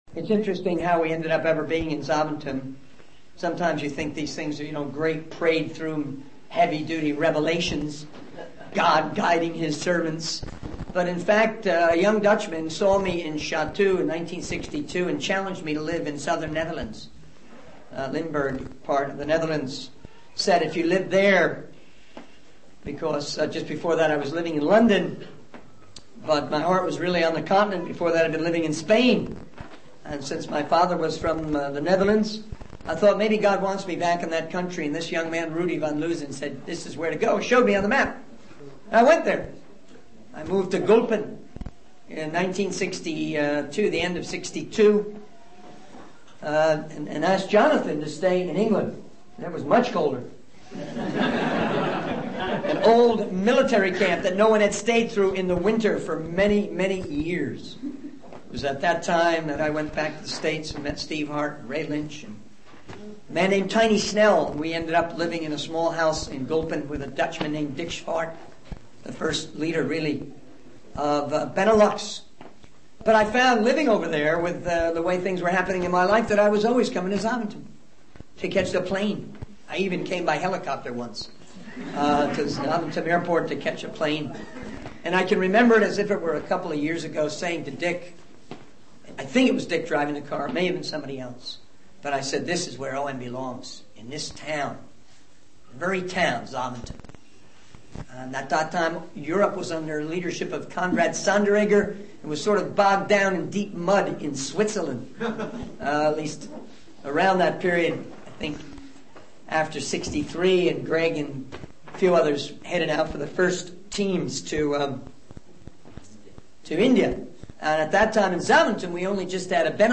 In this sermon, the speaker reflects on a past discussion with friends about their commitment to never leave each other. The speaker then transitions to discussing the importance of worship and offering our lives to God as a sacrifice.